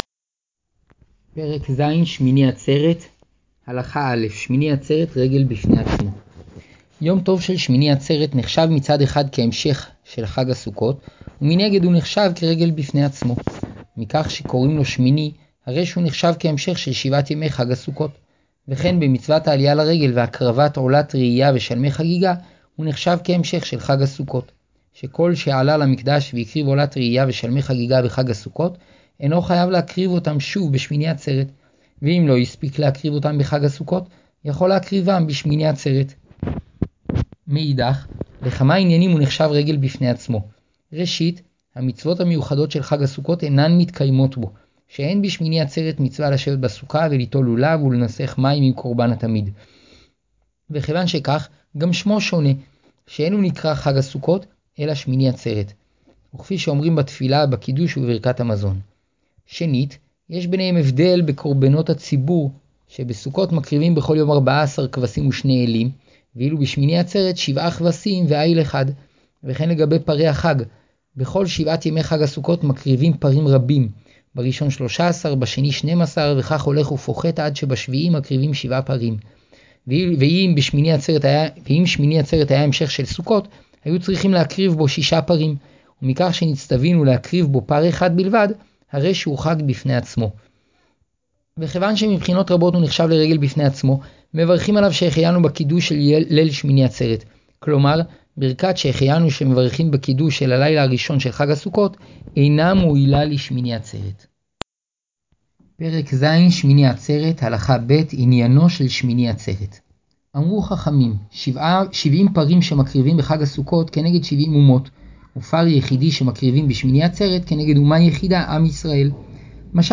האזינו: פניני הלכה סוכות בקבצי שמע